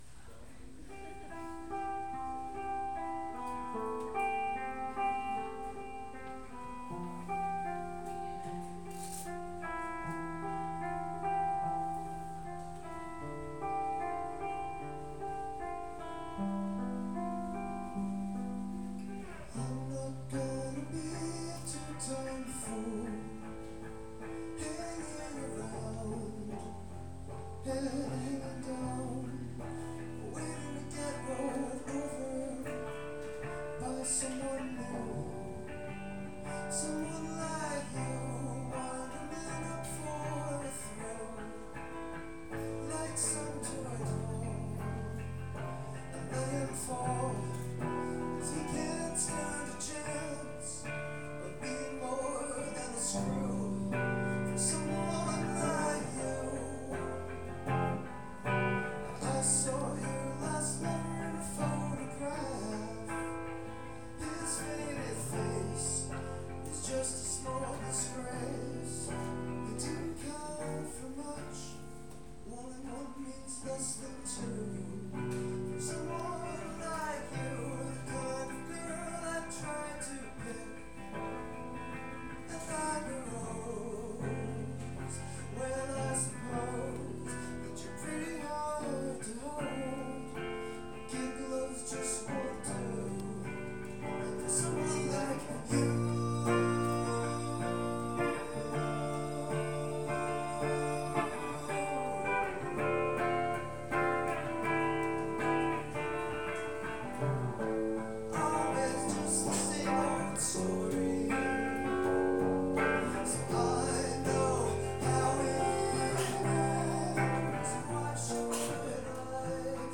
It’s a café that doubles as a venue at night, with good food and a small but excellent selection of beers.
Live MP3s